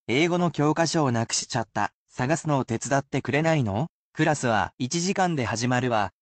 Though you should certainly repeat after the words to assist you in learning them,the sentences are at normal speed, so you probably would benefit most from using these as listening practice.